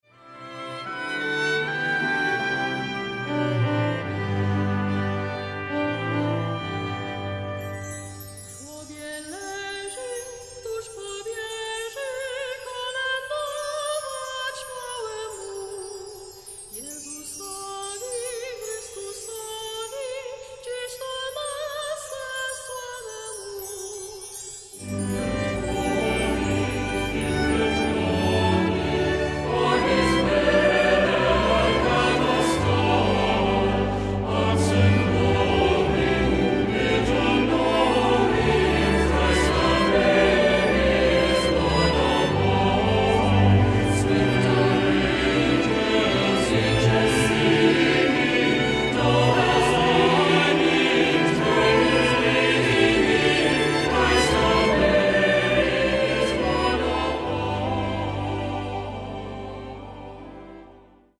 Accompaniment:      Organ
Music Category:      Choral